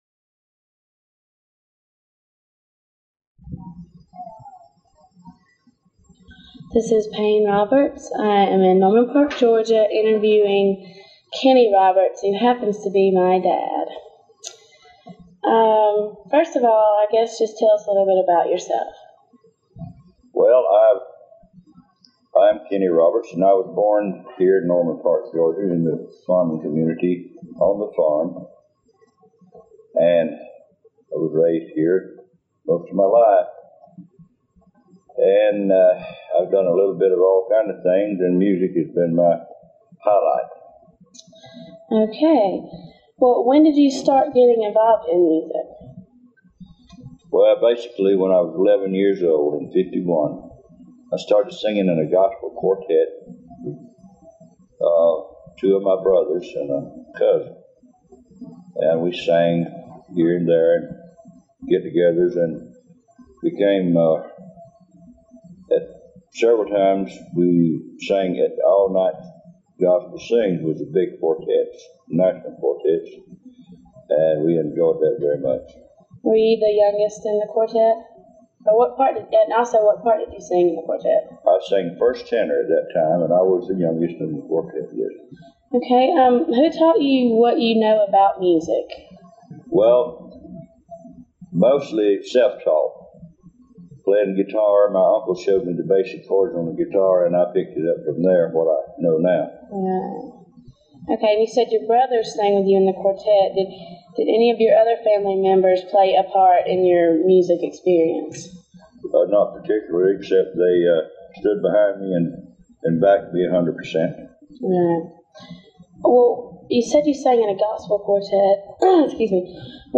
Audio file from cassette tape. Part of the South Georgia Folklife Project at Valdosta State University Archives and Special Collections. Topics include Country and Rock Music. Includes original songs.